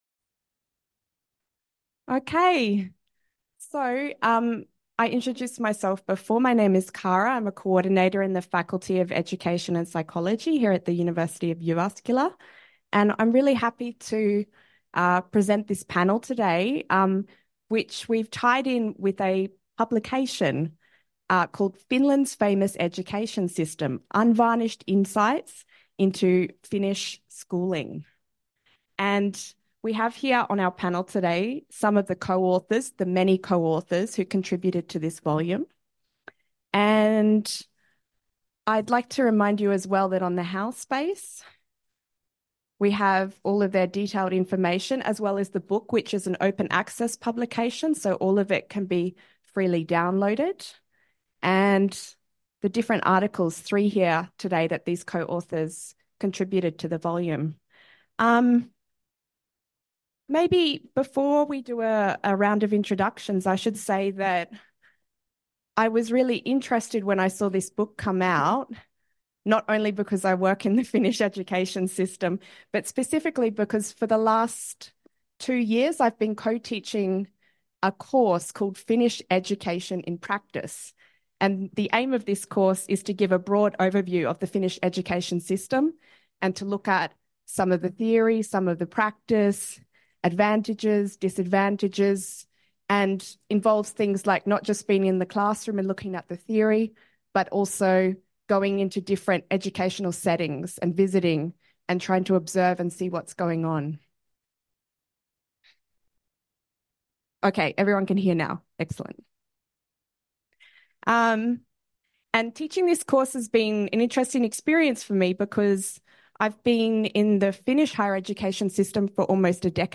Finland's Famous Education System_Panel — Moniviestin